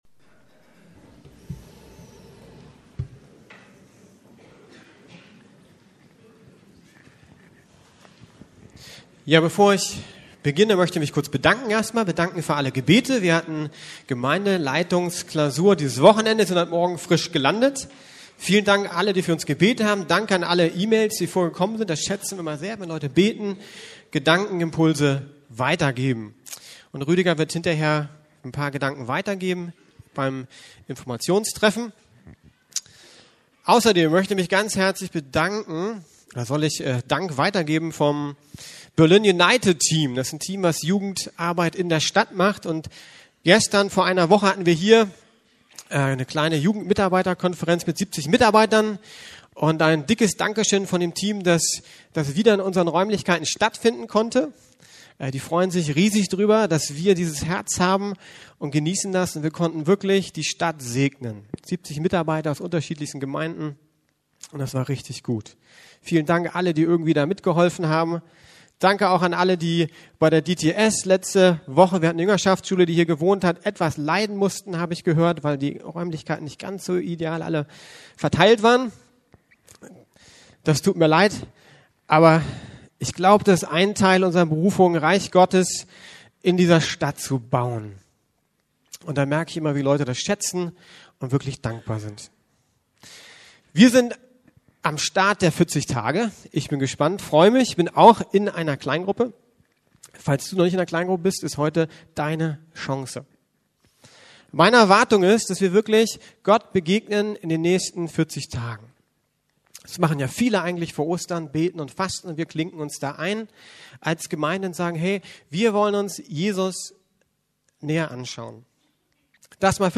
Eine lebensverändernde Begegnung ~ Predigten der LUKAS GEMEINDE Podcast